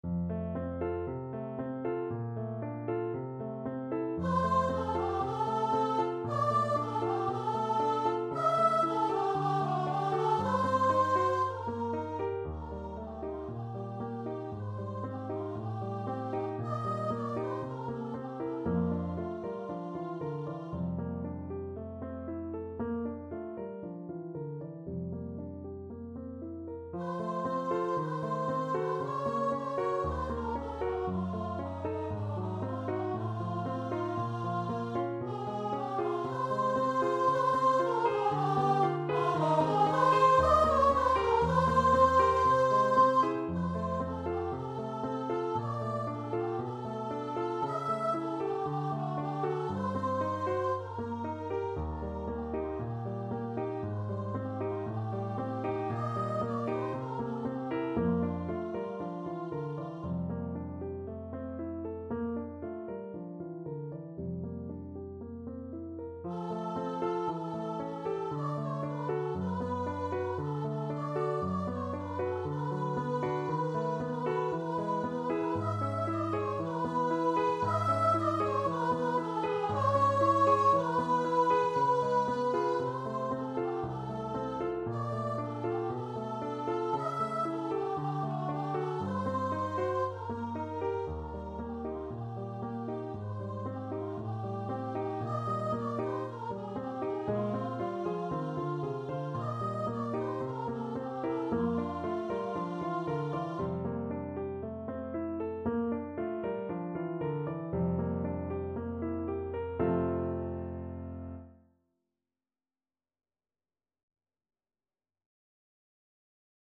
Allegro moderato =116 (View more music marked Allegro)
Voice  (View more Intermediate Voice Music)
Classical (View more Classical Voice Music)